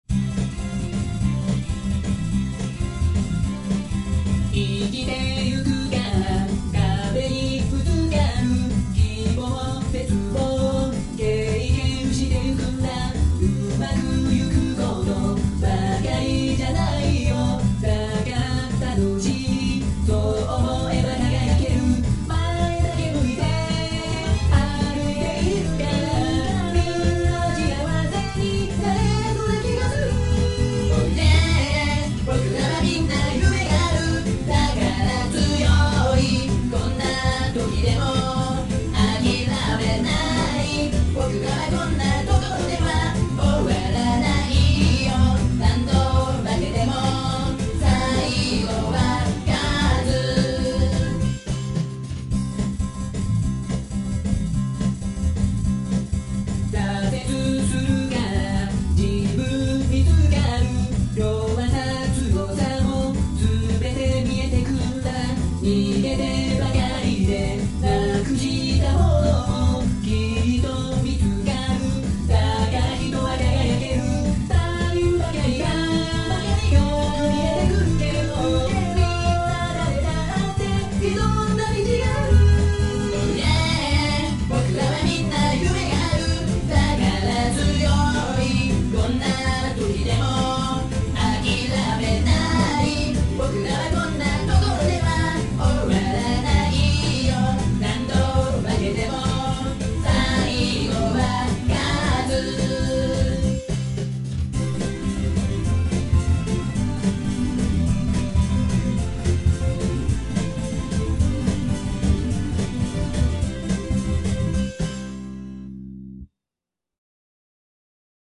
【1.6倍速】